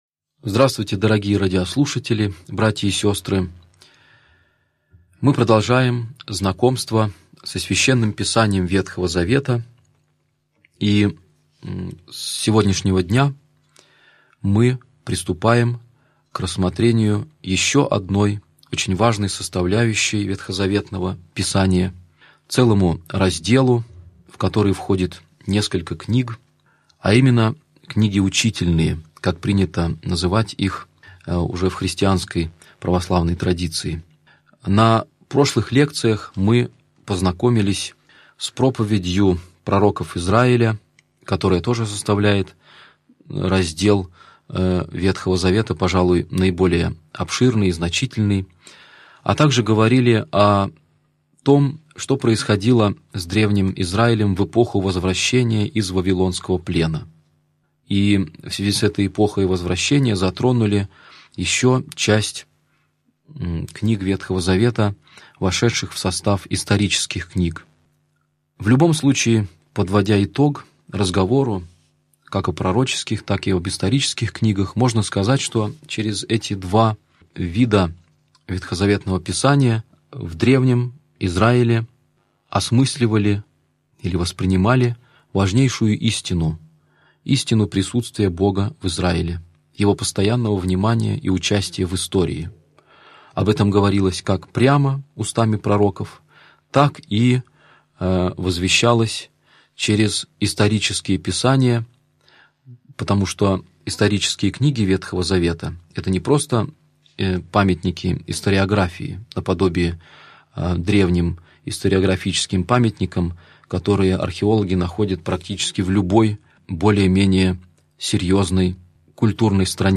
Аудиокнига Лекция 28. Книги учительные | Библиотека аудиокниг